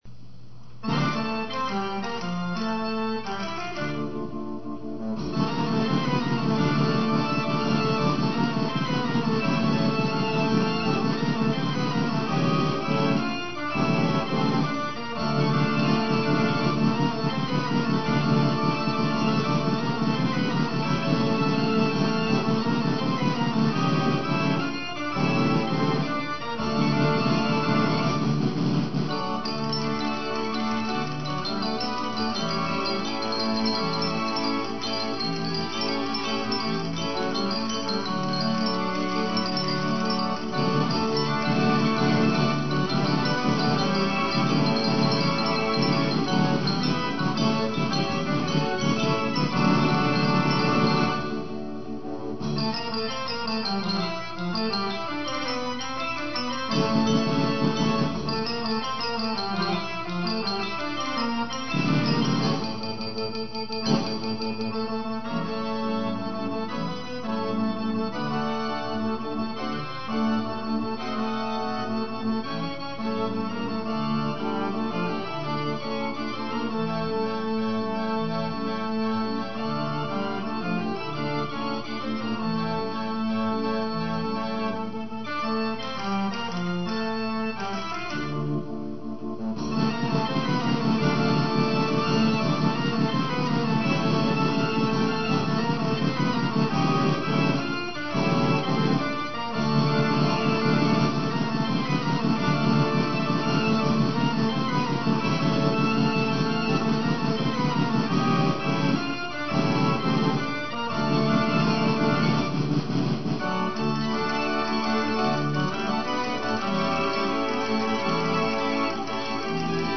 Fox Trot